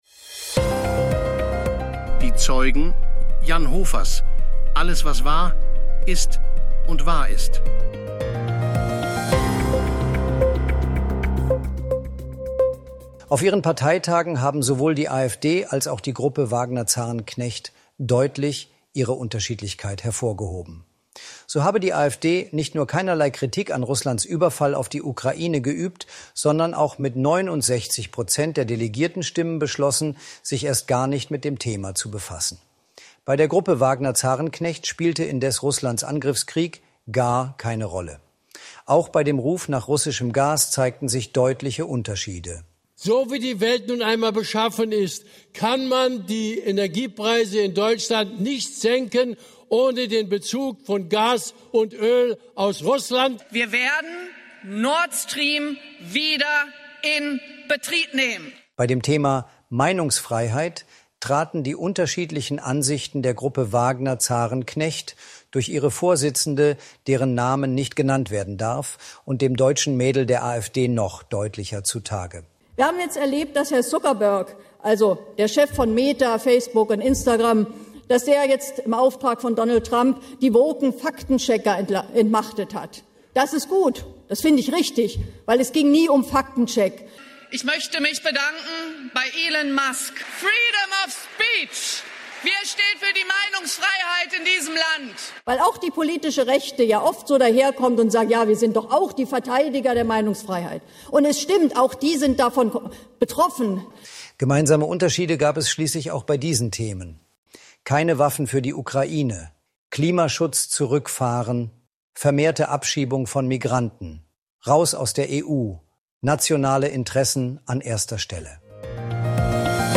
(100% KI-ssel)